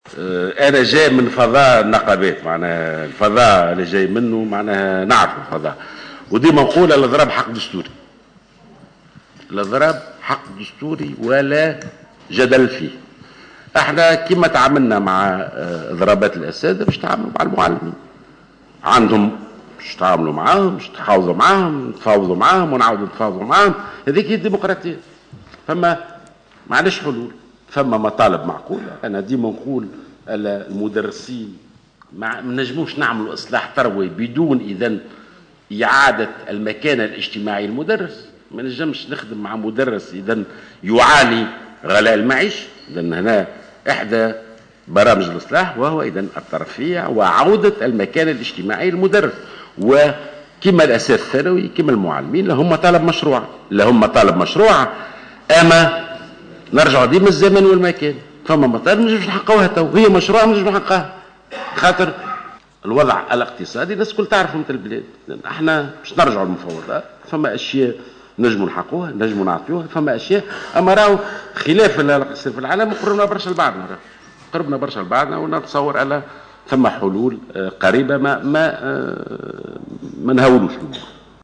وأضاف جلول، خلال ندوة صحفية صباح اليوم الاربعاء أن الوزارة ستتعامل مع إضراب المعلمين بمثل ما تعاملت مع إضراب أساتذة التعليم الثانوي وستتفاوض معهم إلى حين الوصل إلى اتفاق.